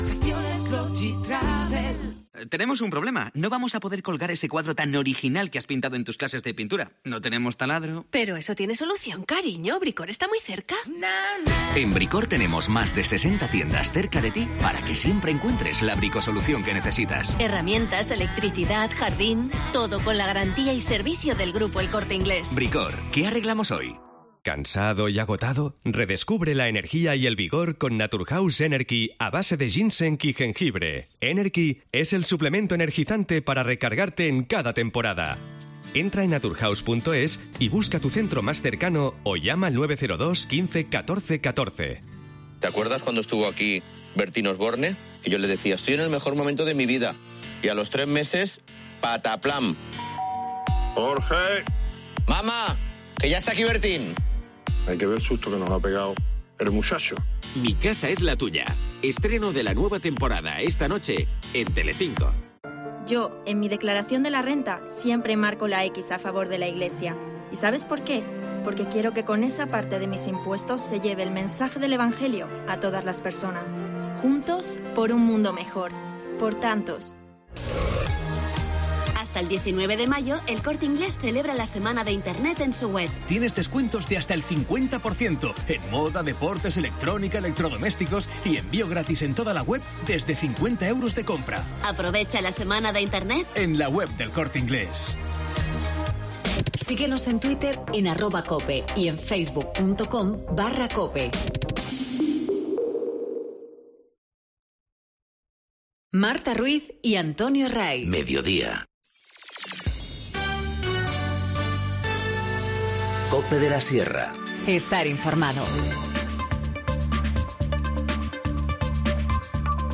Informativo Mediodía 10 mayo 14:20h